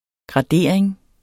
Udtale [ gʁɑˈdeˀɐ̯eŋ ]